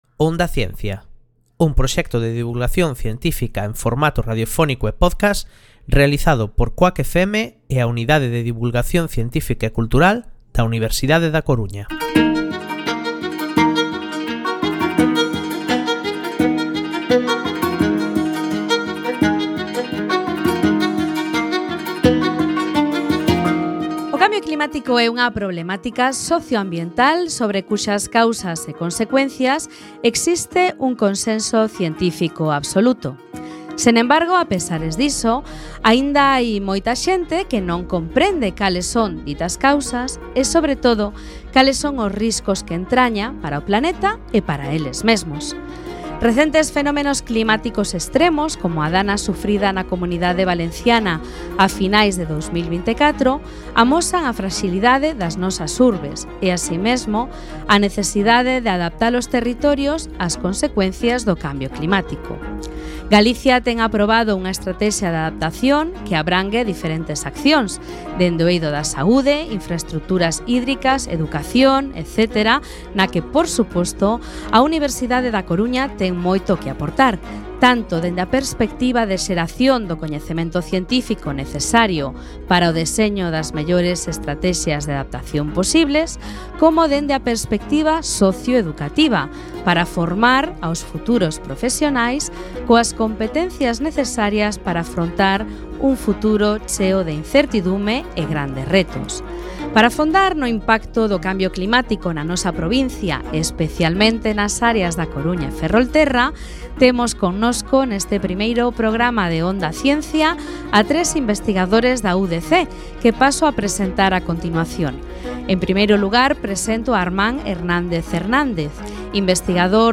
Para iso, conversamos con tres expertos da Universidade da Coruña cunha extensa traxectoria investigadora neste eido: